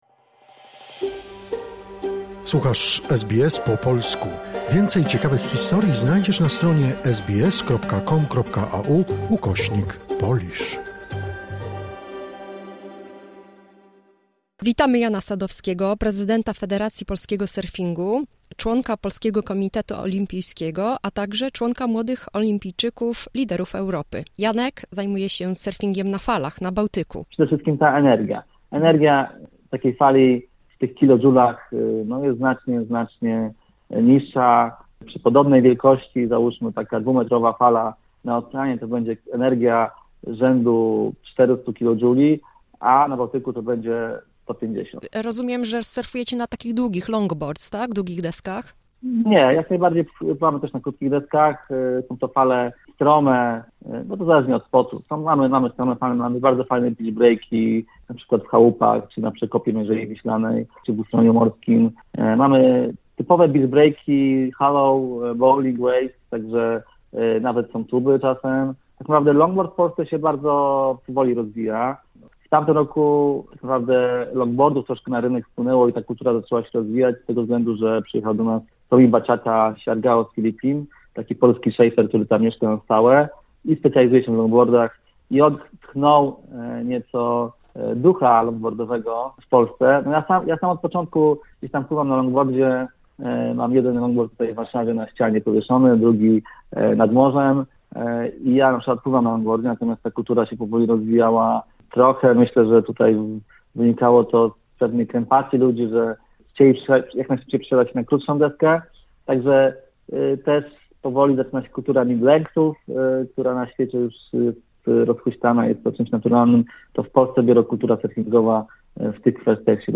Druga część rozmowy